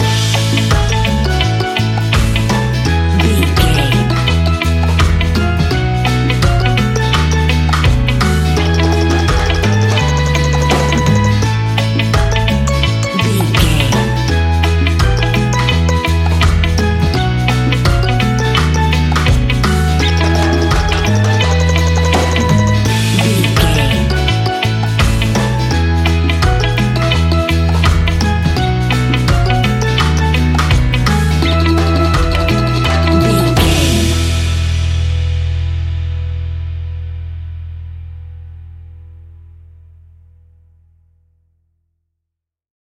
Ionian/Major
Slow
steelpan
happy
drums
percussion
bass
brass
guitar